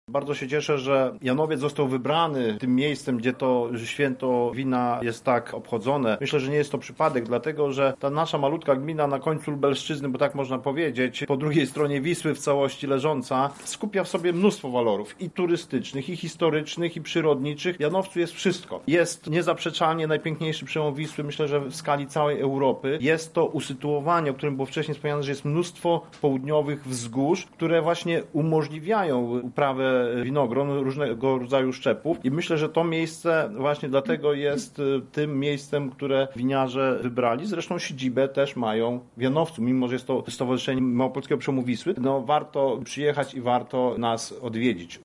Na to pytania odpowiada wójt Jan Gędek, który docenia możliwość promowania regionu poprzez organizowanie tego typu cyklicznych imprez.